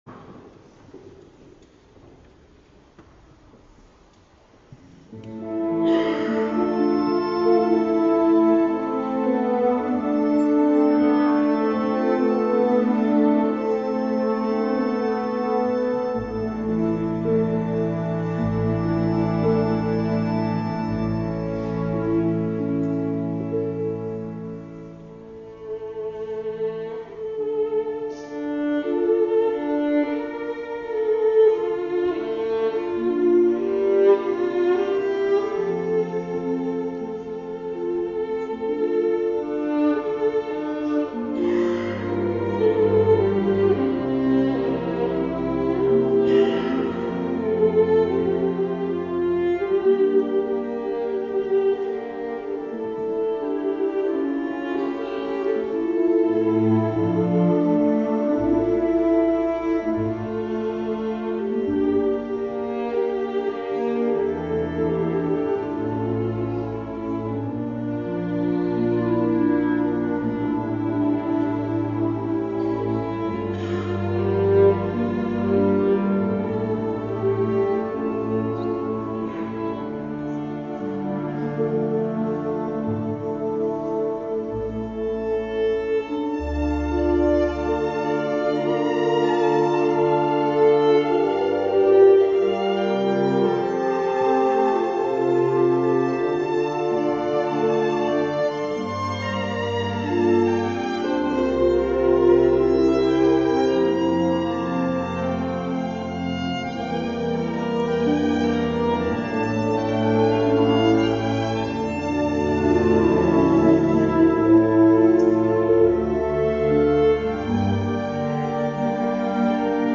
Concertul de la  Sinagoga din Cetate
The Opera concert at the Synagogue - The Concert